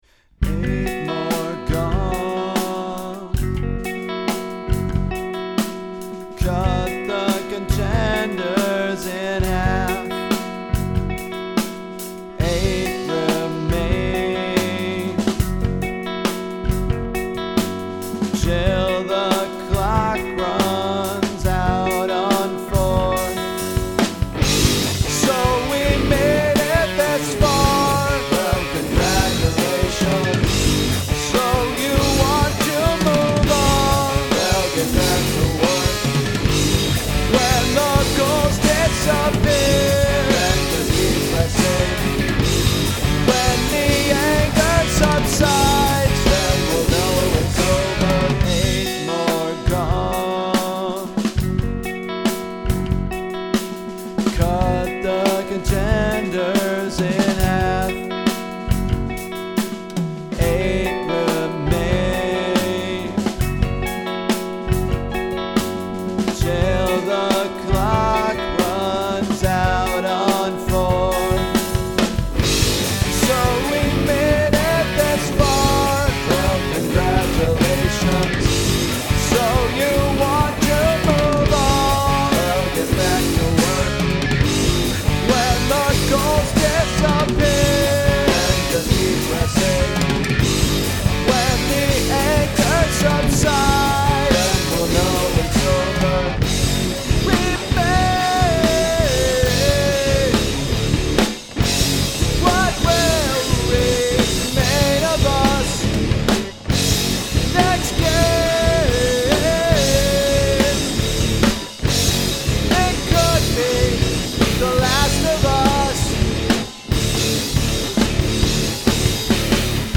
The doubling, combined with the lower register, makes it sound incredibly sarcastic which I like a lot.
Position changes and string jumps give it an extremely high margin for error. The song is also mostly in seven, because I like using seven and it had been a while.